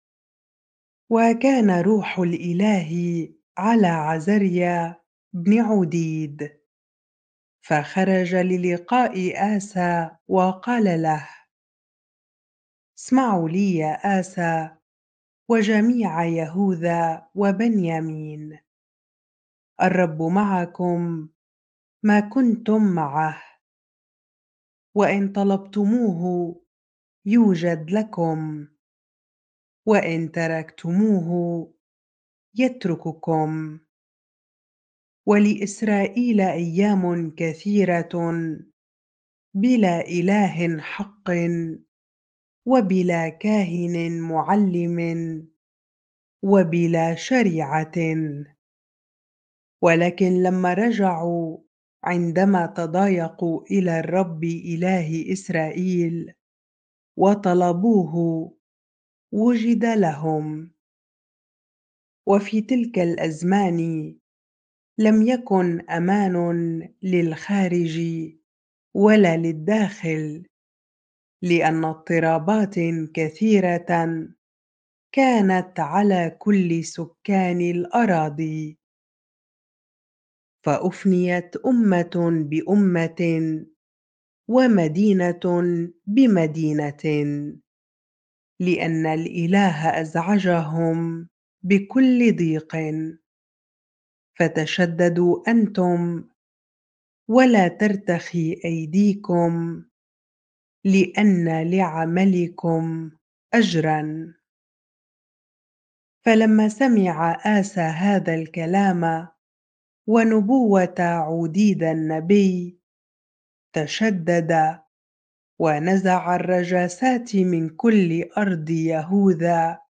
bible-reading-2 Chronicles 15 ar